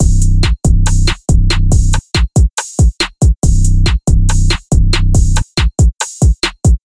硬式陷阱鼓和808鼓
描述：我创造了这些鼓，是为了一个非常沉重的陷阱节拍！ 享受；)
Tag: 140 bpm Trap Loops Drum Loops 1.16 MB wav Key : B FL Studio